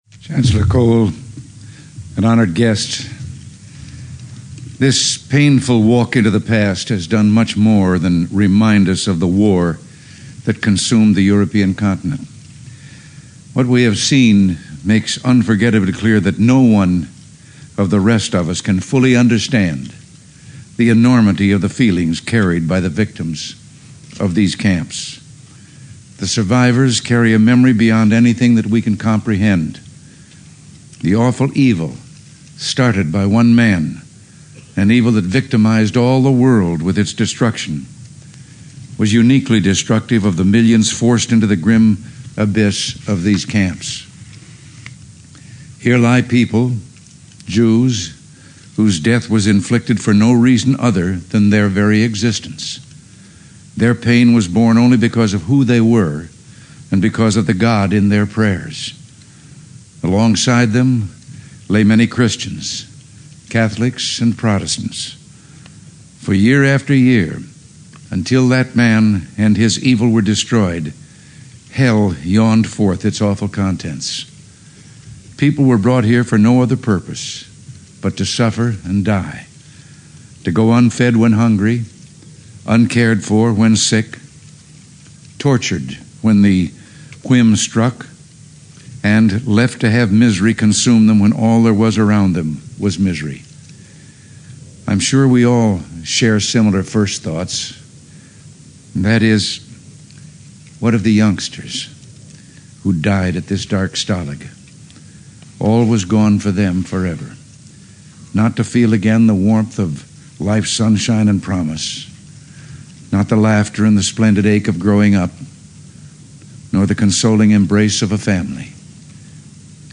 Remarks at the Bergen-Belsen Concentration Camp
delivered 5 May 1985, Federal Republic of Germany
Audio mp3 of Address